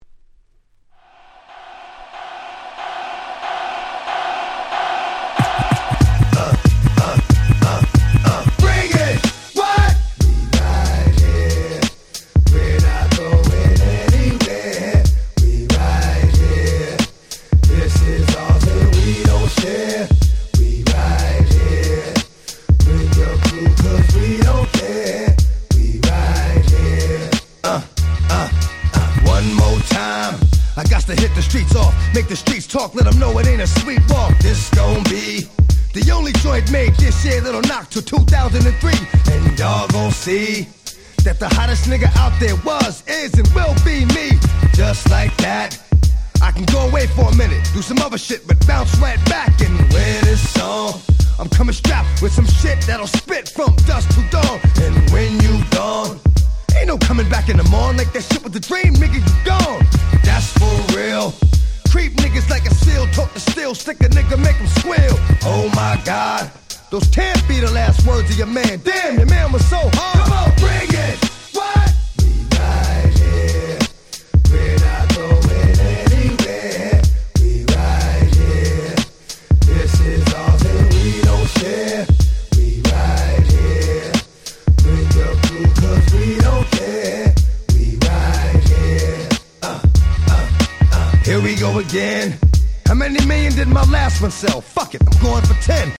01' Smash Hit Hip Hop !!